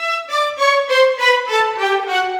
Rock-Pop 20 Violins 01.wav